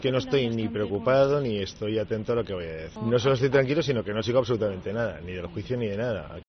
En declaraciones a los medios a las puertas del Consejo Jurídico Consultivo, del que es miembro como expresident, Camps ha insistido en varias ocasiones en que "el derecho a la defensa no da derecho a la ofensa", en alusión a la declaración de hoy de Costa, y ha dicho que le parece "inexplicable" que ahora se le mencione durante el juicio.